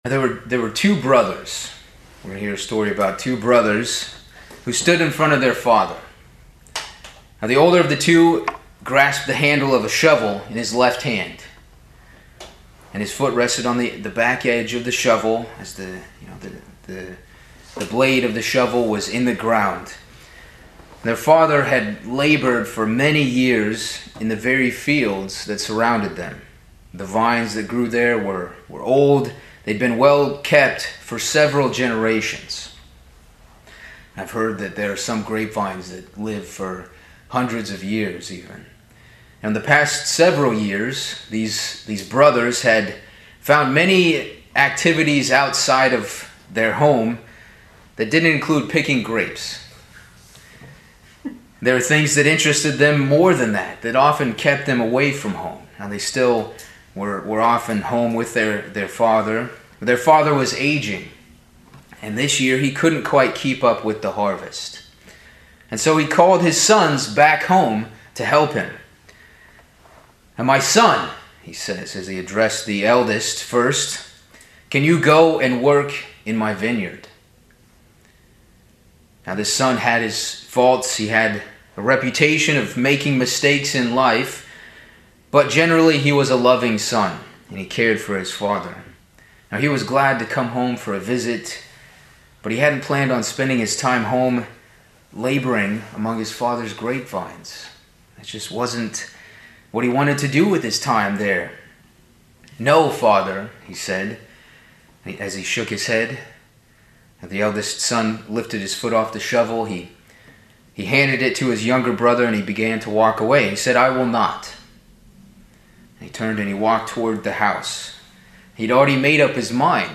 Sermon
Given in Hartford, CT